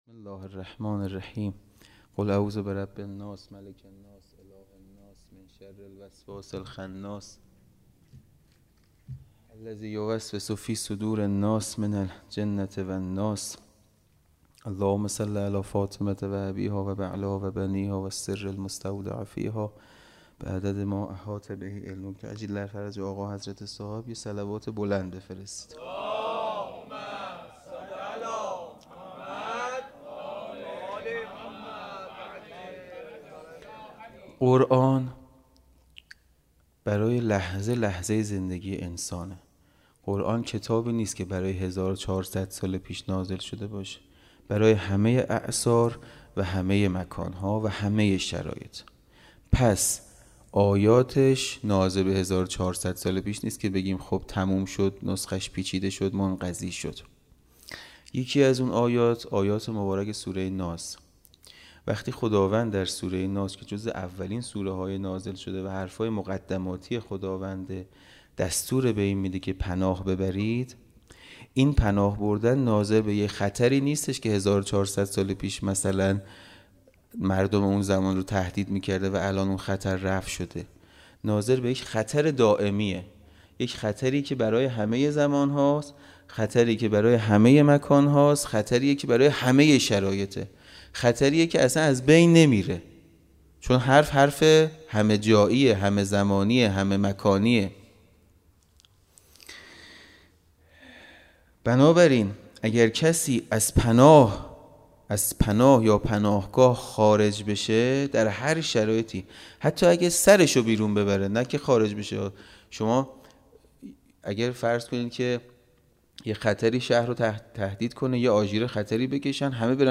خیمه گاه - حسینیه کربلا - شب اول محرم- سخنرانی